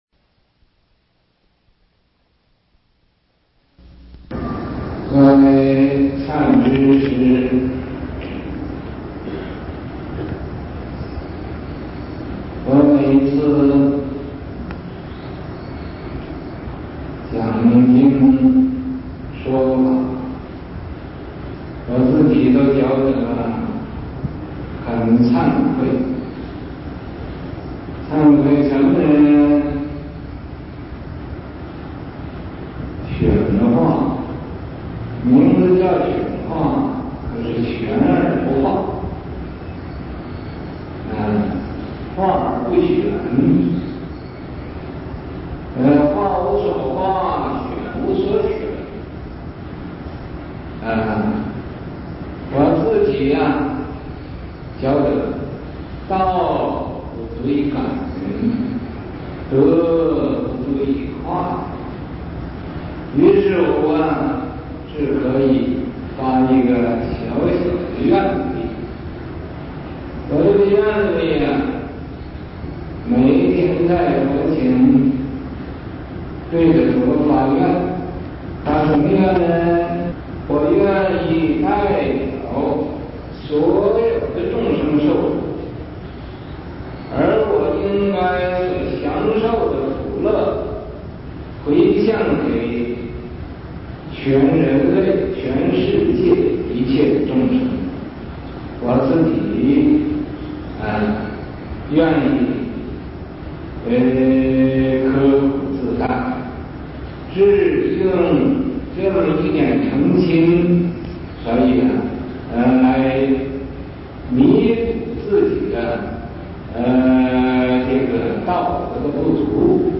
Dharma Library : 1989 年 美 加 地 區 開 示< | >1989 USA Instructional Talks< | >1989 Niên Mỹ Gia Địa Khu Khai Thị